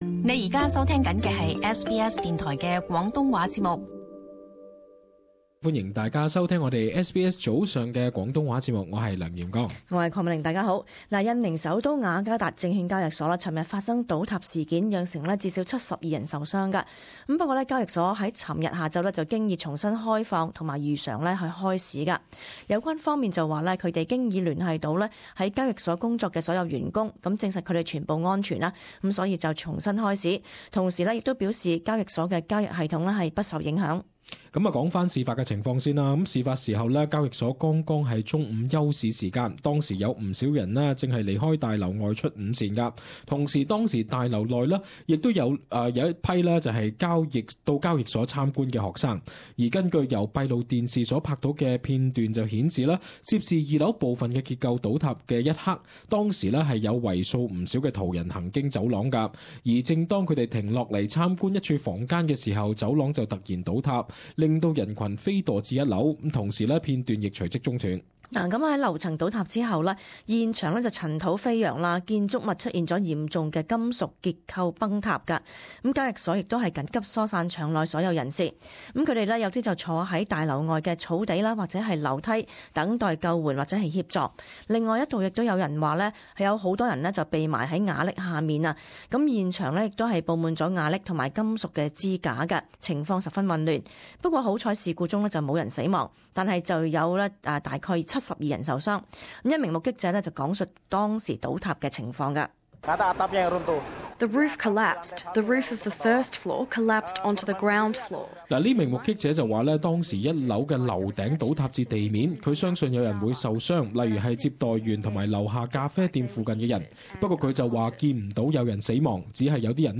【时事报导】印尼证券交易所走廊倒塌